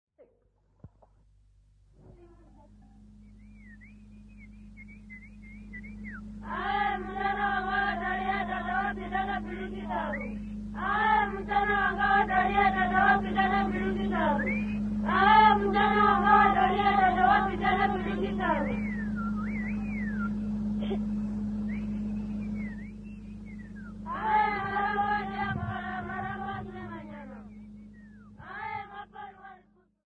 JBFT09-JV25-6.mp3 of Music for musevhetho girls' circumcision ceremony